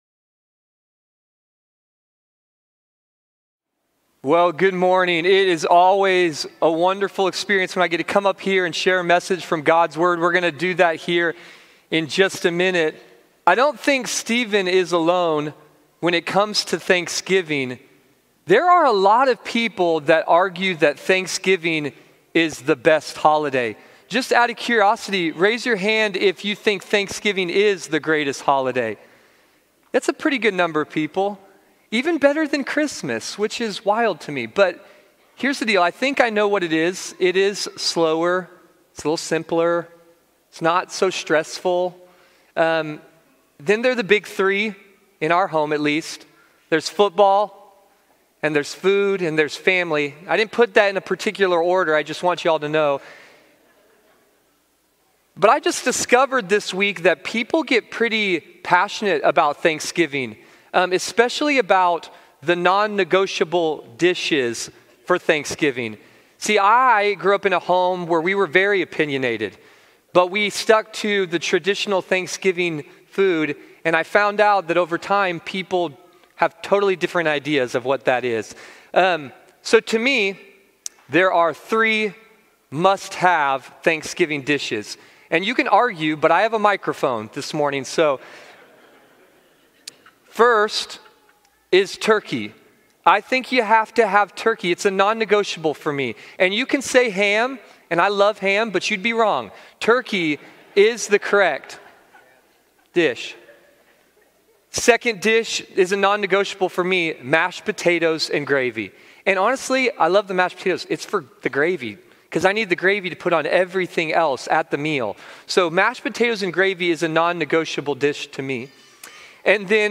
Series: Stand Alone, Sunday Morning